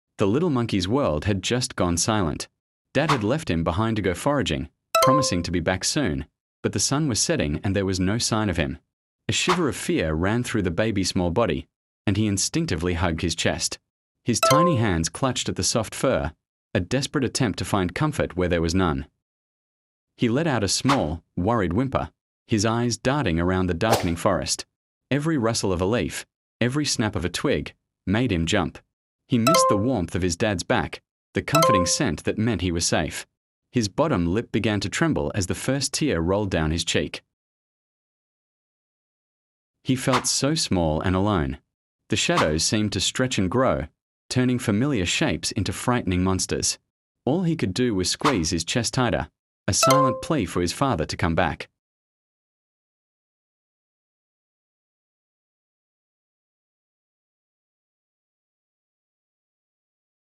So pity baby monkey hug sound effects free download
So pity baby monkey hug chest make lips so scare when alone